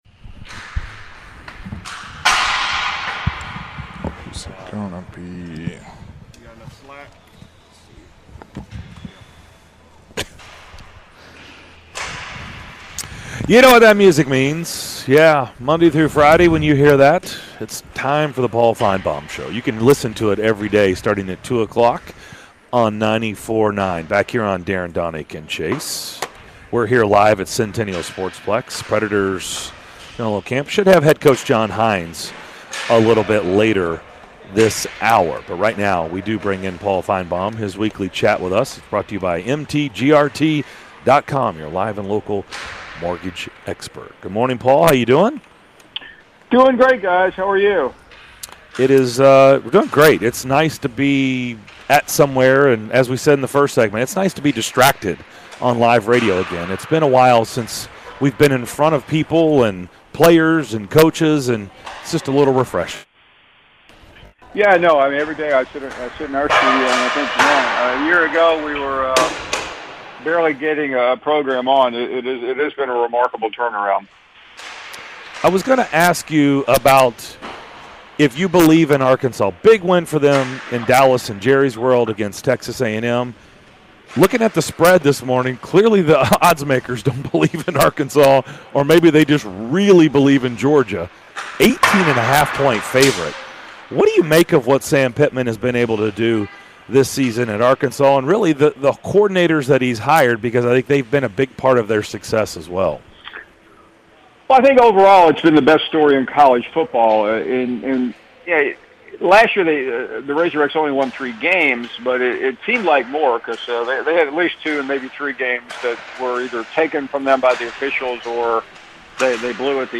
ESPN and SEC Network's Paul Finebaum joined the DDC to discuss the latest from around the SEC!